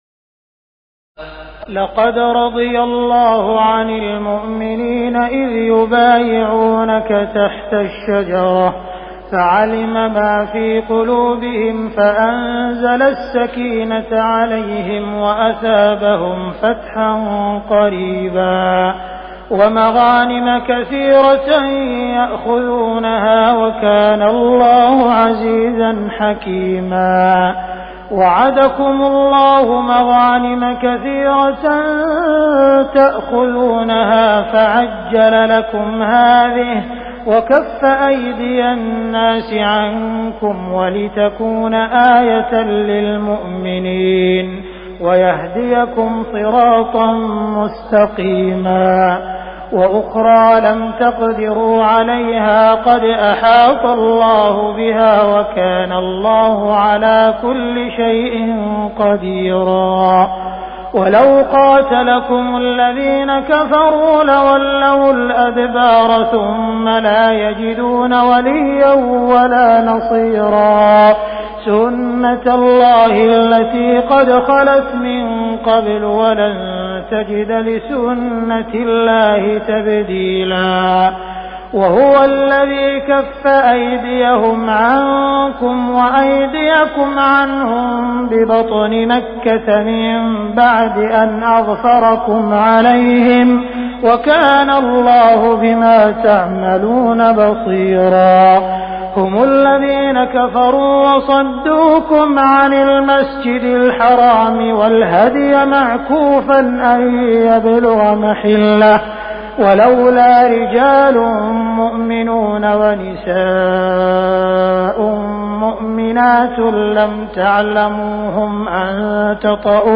تراويح ليلة 25 رمضان 1418هـ من سور الفتح (18-29) الحجرات وق و الذاريات (1-37) Taraweeh 25 st night Ramadan 1418H from Surah Al-Fath and Al-Hujuraat and Qaaf and Adh-Dhaariyat > تراويح الحرم المكي عام 1418 🕋 > التراويح - تلاوات الحرمين